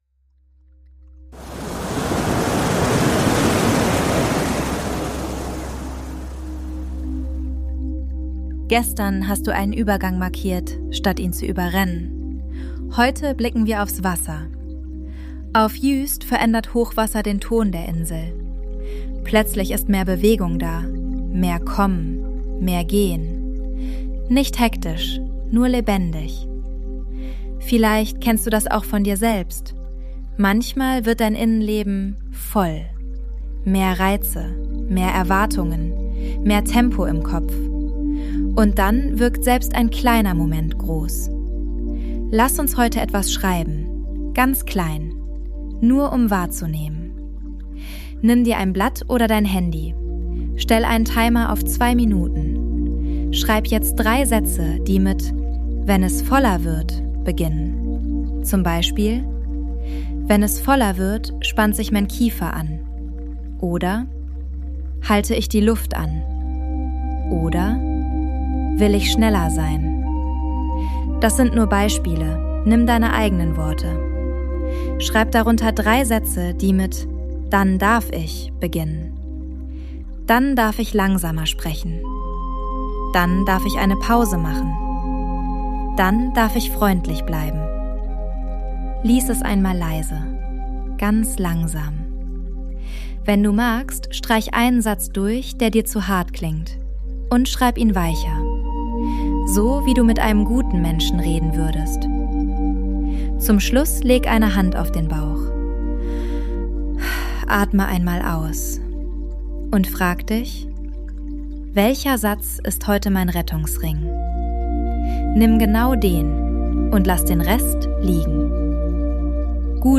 Juist Sounds & Mix: ElevenLabs und eigene Atmos